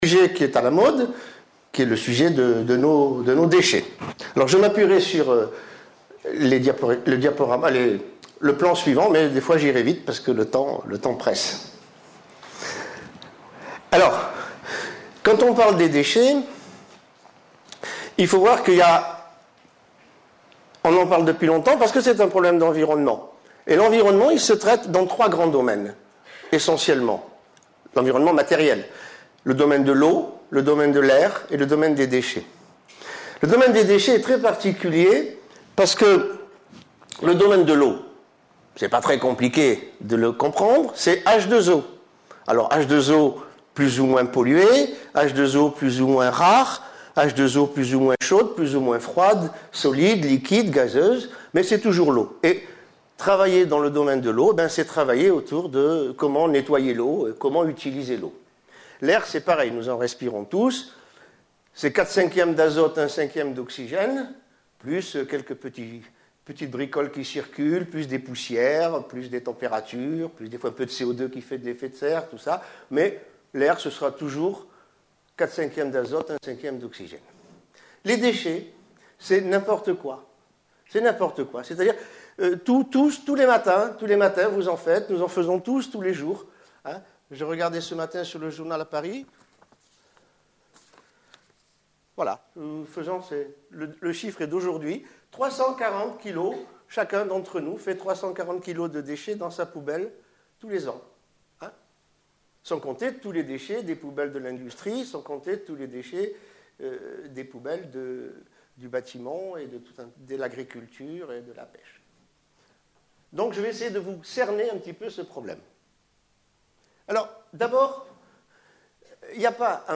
Une conférence de l'UTLS au Lycée
Lycée Fénelon Sainte Marie (75008 Paris)